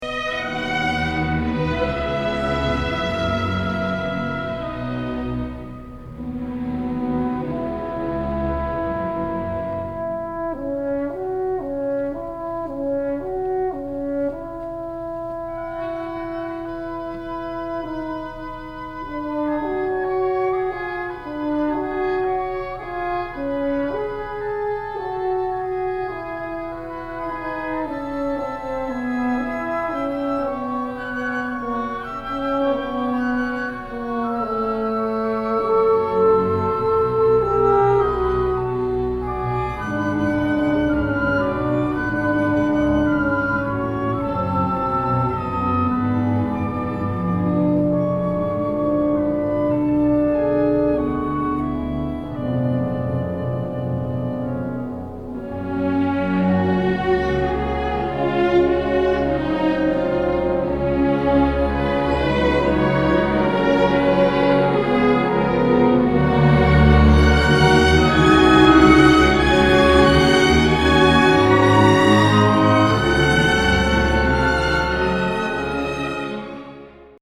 I believe the recording was made with a minidisc recorder, and the only editing I have done is to pull out the excerpts from the context of the entire symphony and add a touch of reverb (our hall is incredibly dry).
Jack Howard Theatre, Monroe, LA
horn solo